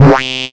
Sweep Up (JW2).wav